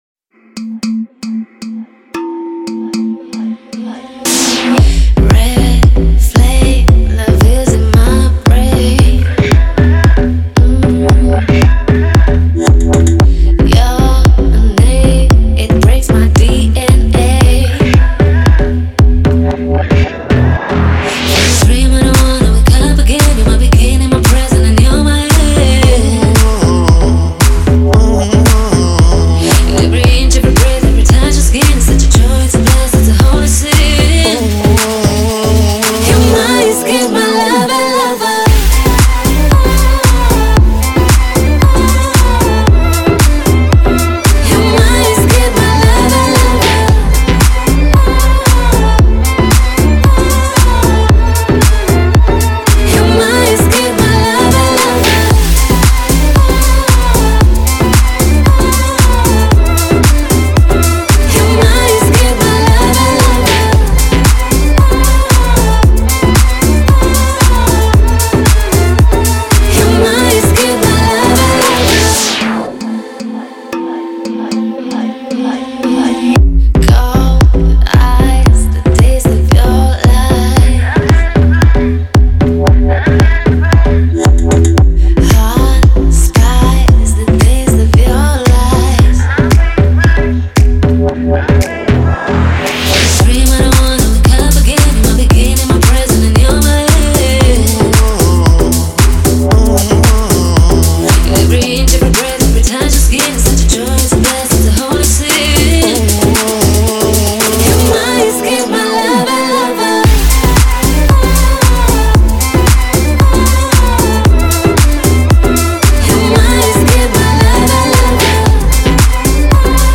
это энергичная танцевальная композиция в жанре EDM